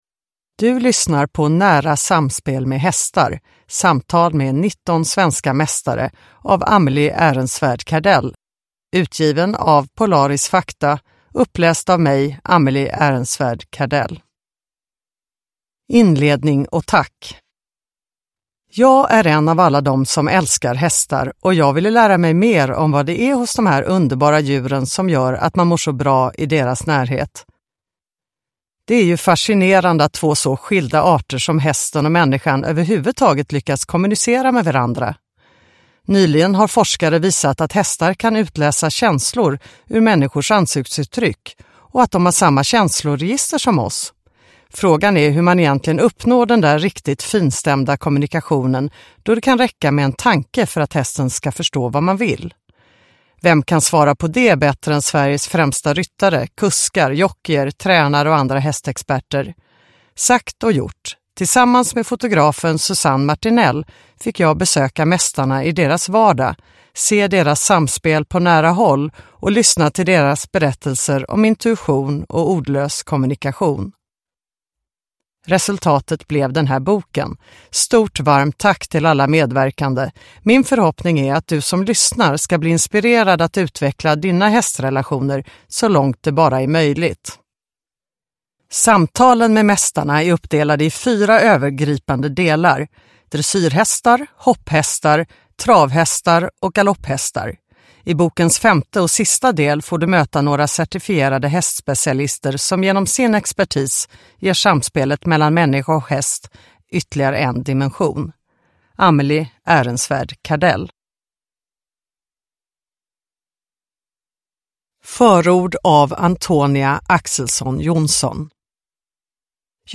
Nära samspel med hästar - Samtal med 19 svenska mästare – Ljudbok – Laddas ner